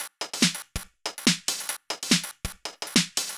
Index of /musicradar/uk-garage-samples/142bpm Lines n Loops/Beats
GA_BeatA142-10.wav